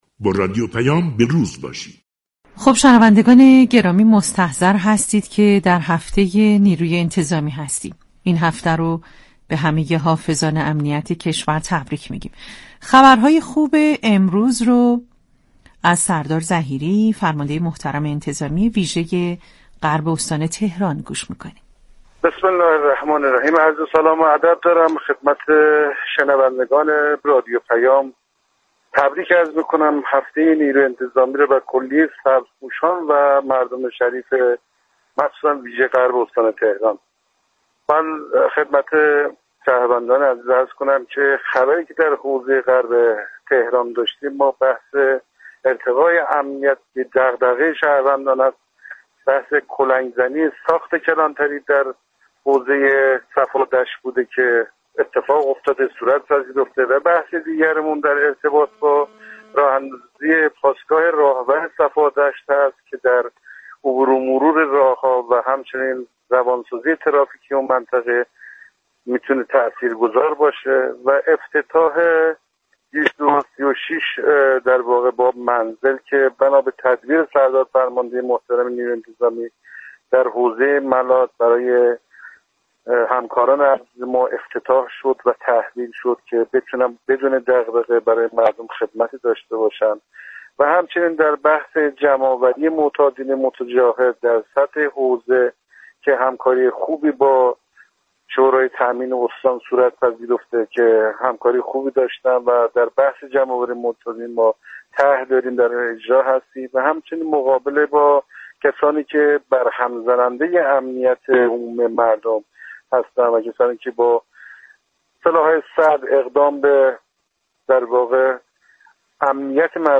سردار ظهیری،فرمانده انتظامی ویژه غرب استان تهران در گفتگو با رادیو پیام ، جزئیاتی از اقدامات نیروی انتظامی ناحیه غرب استان تهران،همزمان با هفته نیروی انتظامی را بازگو كرد .